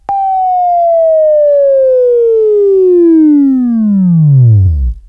Buzz